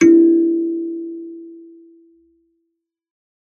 kalimba2_wood-E3-mf.wav